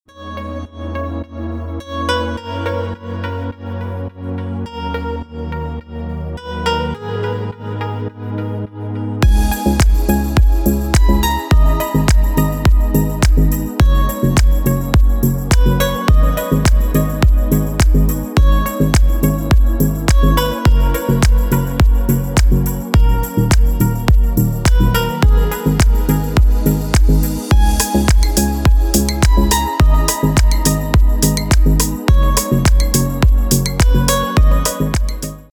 deep house , инструментал , клубные
спокойные